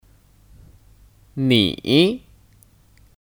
你 (Nǐ 你)